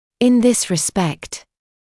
[ɪn ðɪs rɪ’spekt][ин зис ри’спэкт]в этом отношении